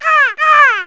bird4.wav